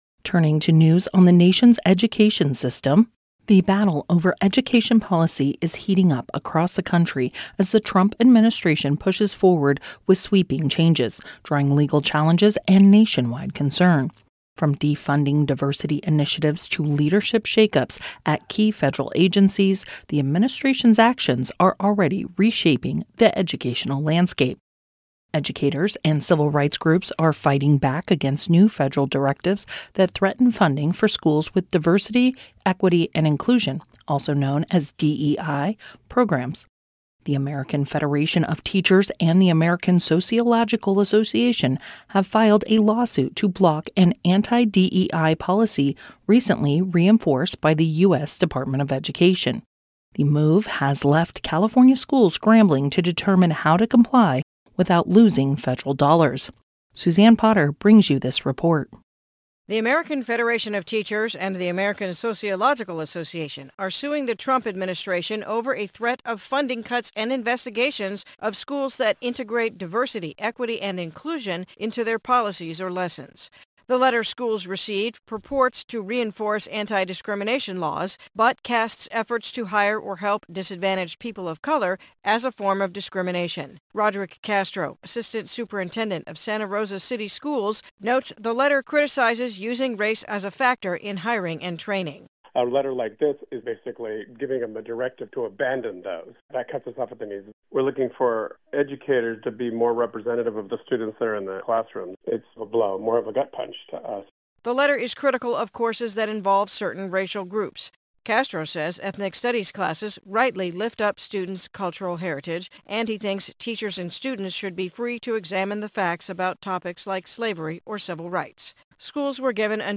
KMUD News